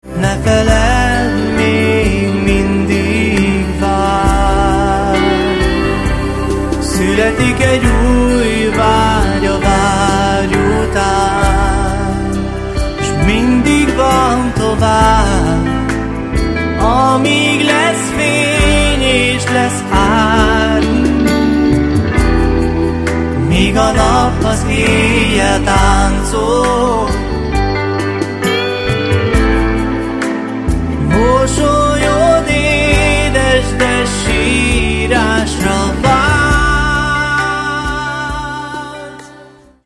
ének, vokál
basszusgitár
nagybőgő
zongora
gitárok
billentyűs hangszerek